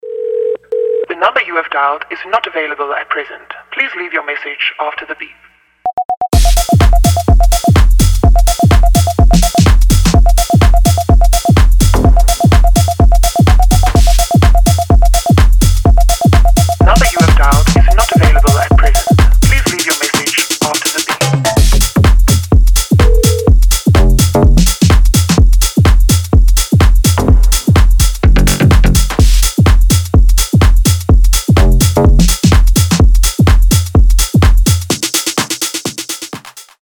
• Качество: 320, Stereo
EDM
Tech House
электронные